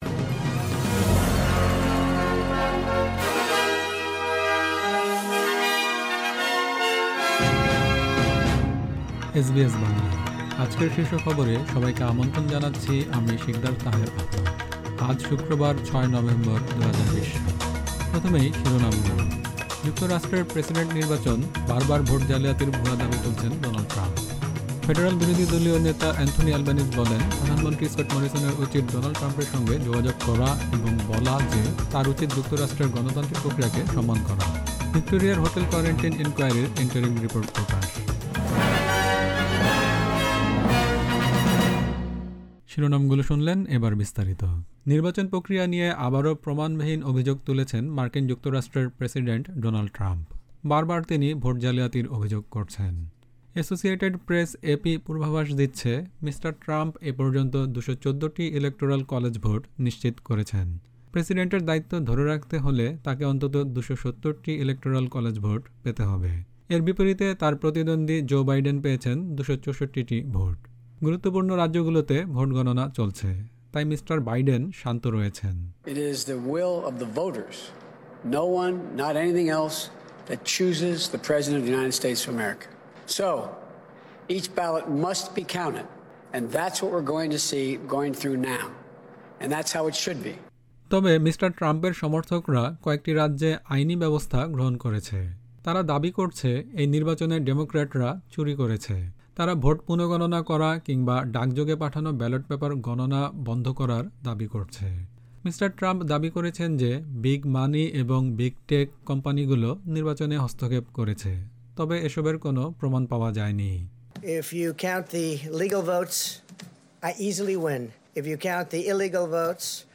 এসবিএস বাংলা শীর্ষ খবর: ৬ নভেম্বর ২০২০